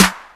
DB - Claps & Snares (2).wav